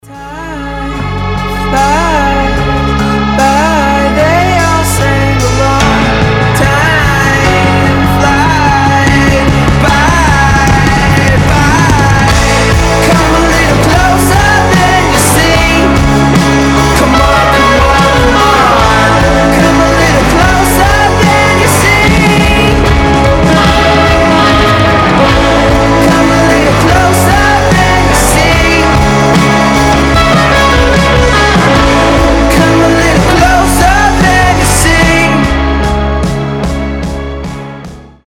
indie rock
психоделический рок